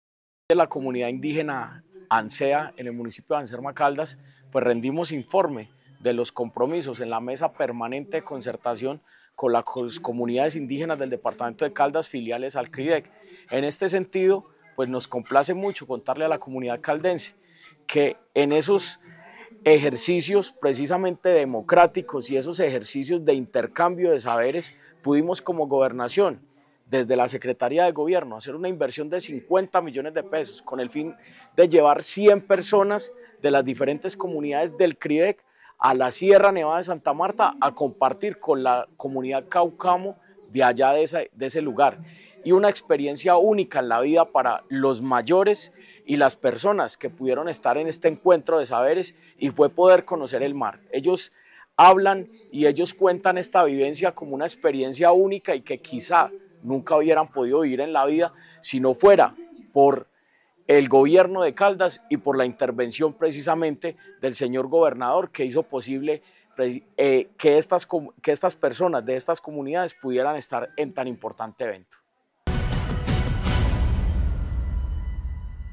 En medio de diálogos constructivos, danzas y músicas propias, así como muestras de la gastronomía de la zona, se llevó a cabo la tercera Mesa de Concertación Indígena de Caldas, realizada en la parcialidad Ansea, del municipio de Anserma.
Jorge Andrés Gómez Escudero, secretario de Gobierno de Caldas.